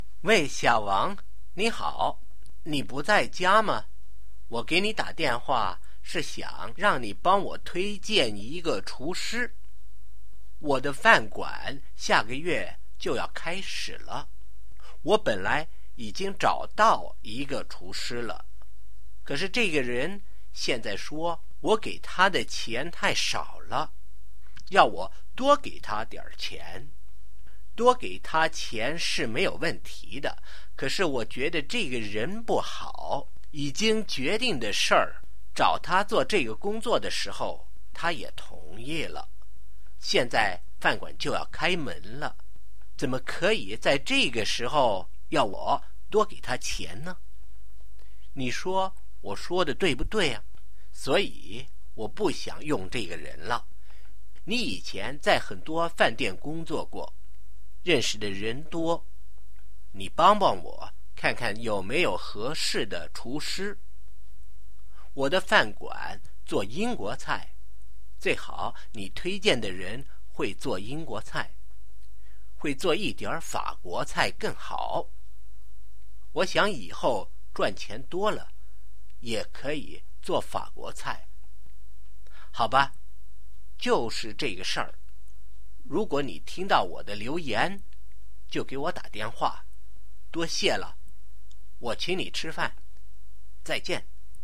Listening Comprehension
Listen (normal)            New Words        Script
voice message/written message